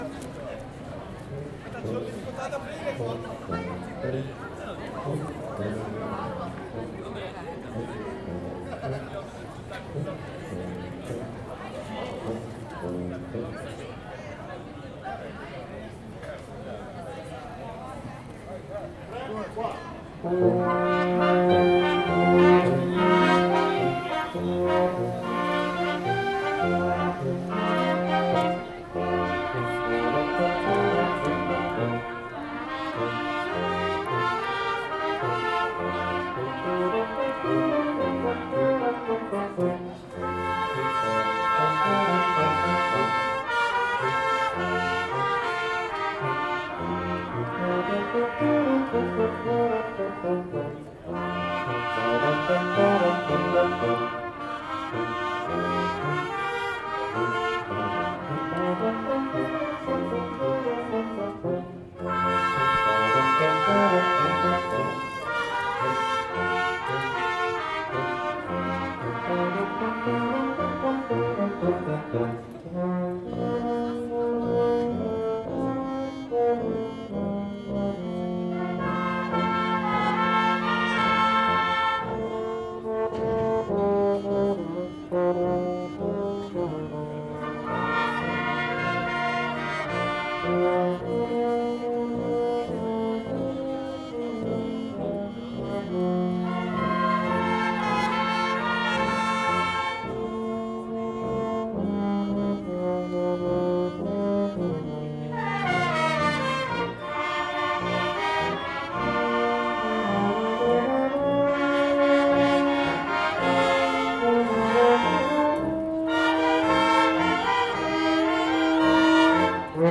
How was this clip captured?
field recordings, sound art, radio, sound walks Brassmusic in the Old Town